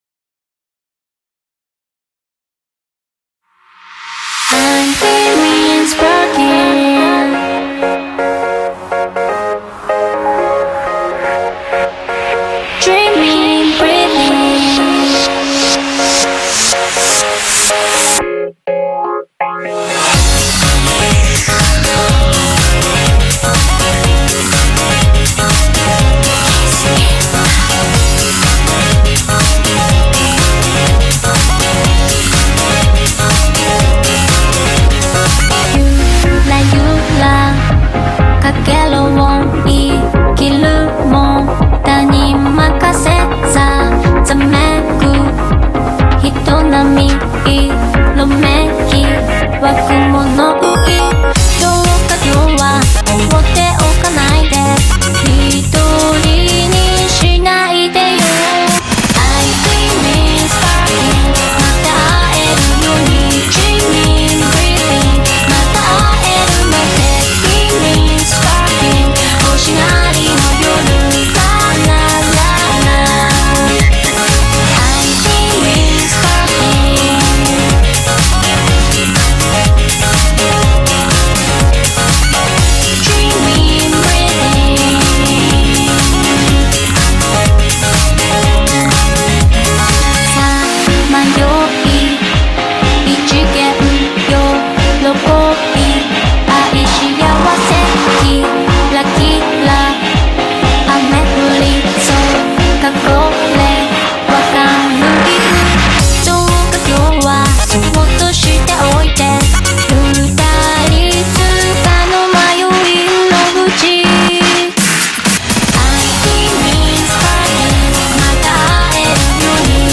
BPM61-123
Audio QualityCut From Video